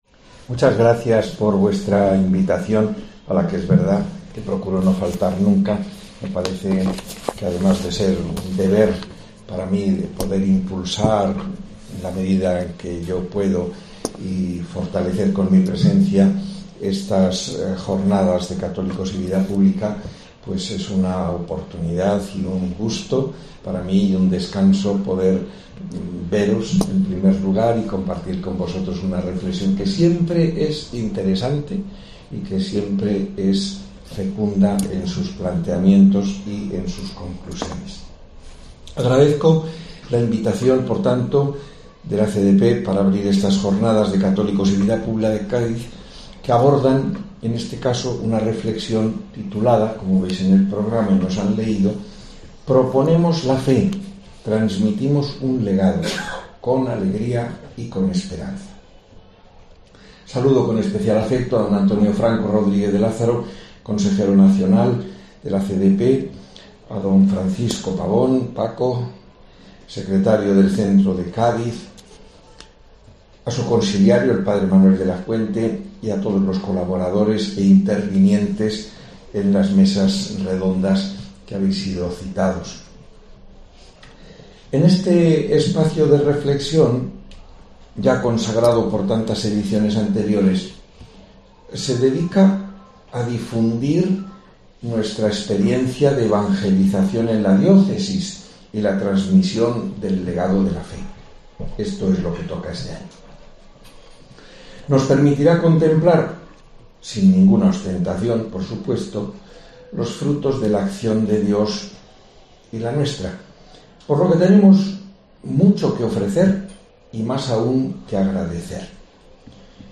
La inauguración, ha estado presidida por el obispo diocesano, Mons. Rafael Zornoza
El Obispo de Cádiz y Ceuta, Rafael Zornoza, en la inauguración de Católicos y Vida Pública